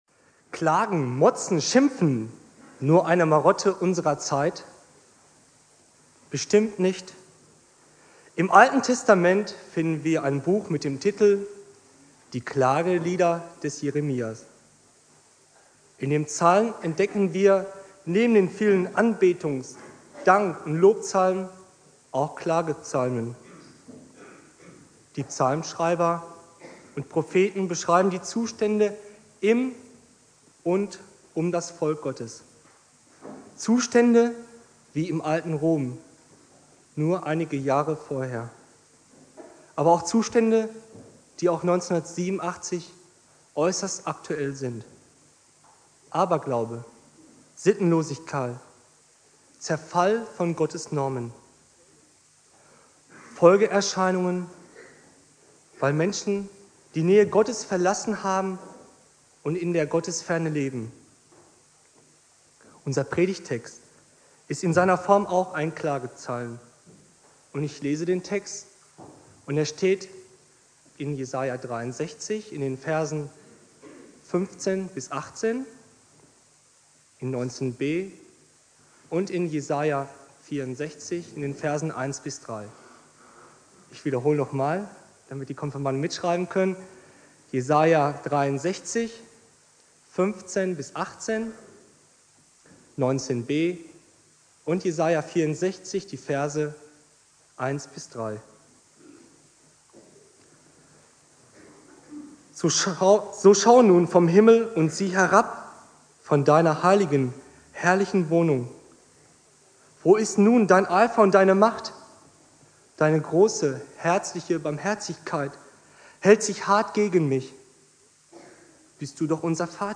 Predigt
2.Advent